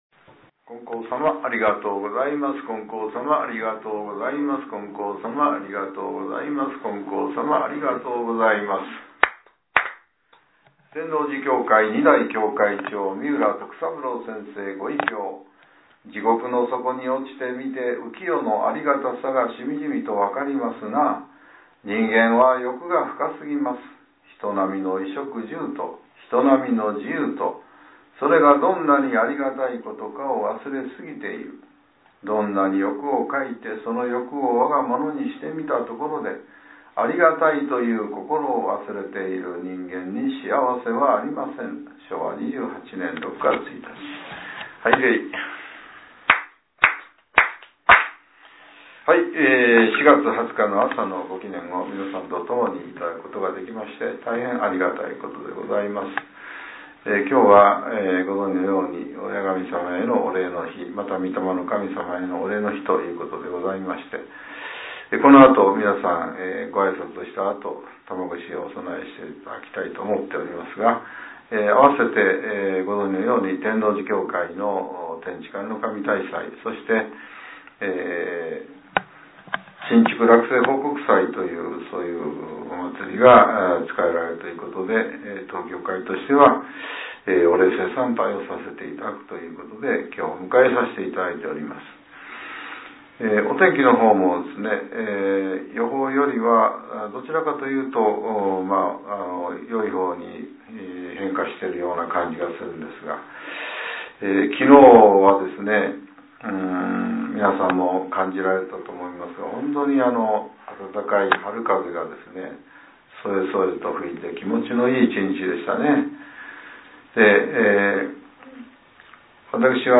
令和７年４月２０日（朝）のお話が、音声ブログとして更新されています。